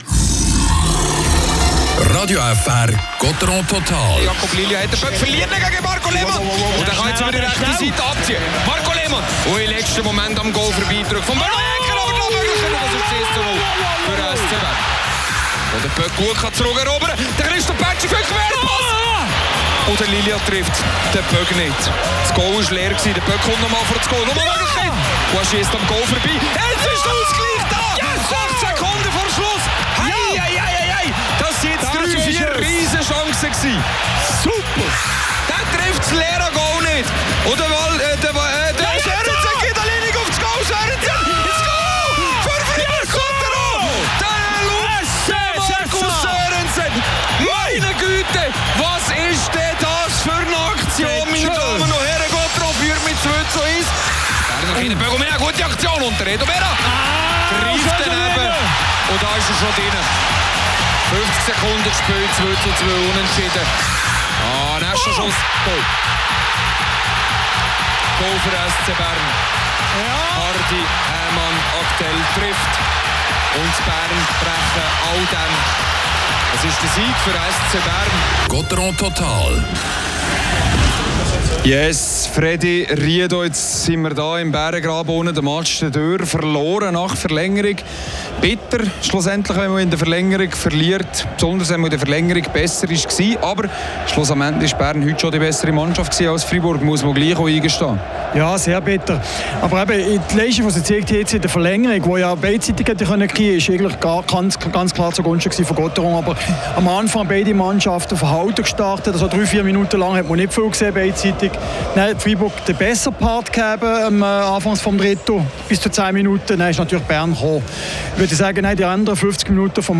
es gibt Stimmen aus dem Team.